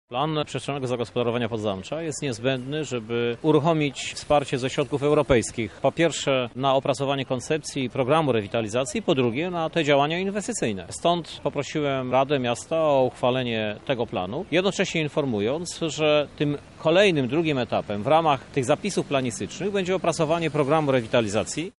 Wyjaśnia prezydent Krzysztof Żuk.